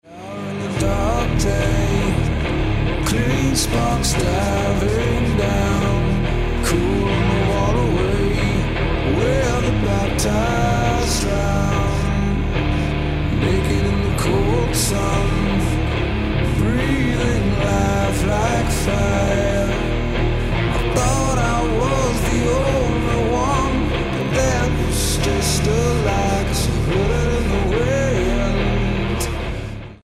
voc, gtr
drums
bass